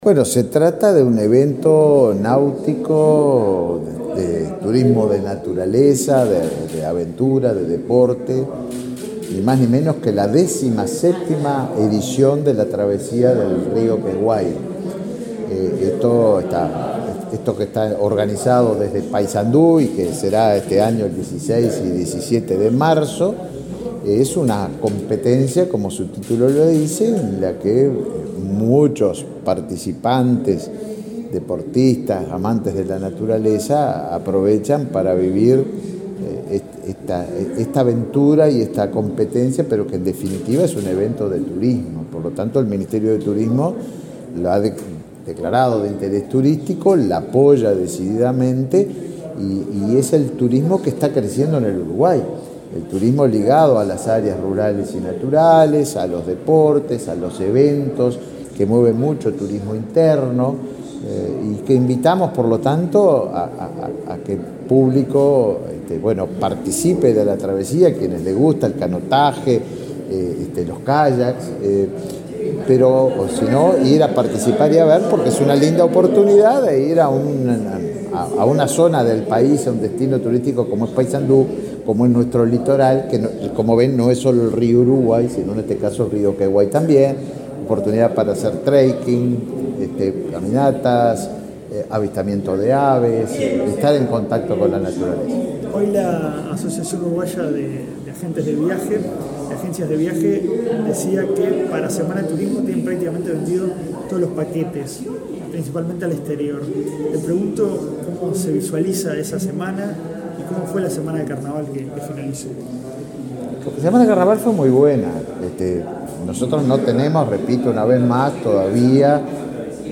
Declaraciones del ministro de Turismo, Tabaré Viera
Este miércoles 28 en Montevideo, el ministro de Turismo, Tabaré Viera, dialogó con la prensa, luego de realizar el lanzamiento de la 17.ª Travesía